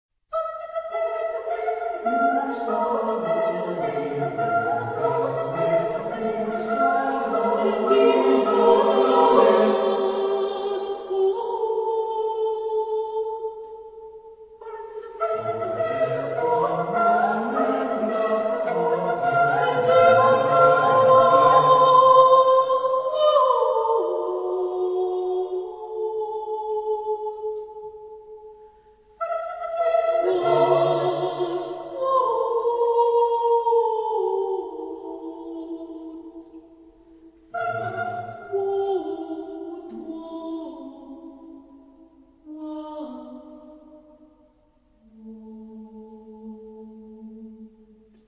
for unaccompanied voices
Genre-Style-Form: Secular ; Choir
Type of Choir: SSATB  (5 mixed voices )
Tonality: A minor